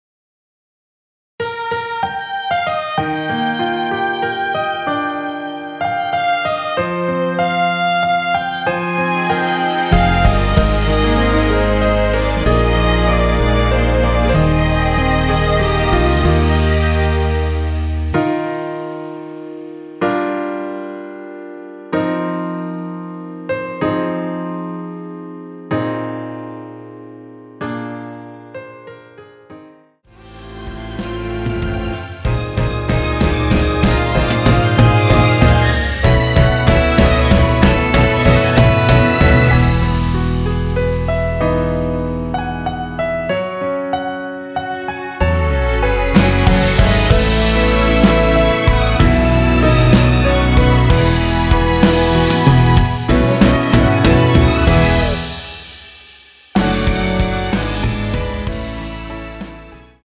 원키에서(+4)올린 MR입니다.
Eb
앞부분30초, 뒷부분30초씩 편집해서 올려 드리고 있습니다.
중간에 음이 끈어지고 다시 나오는 이유는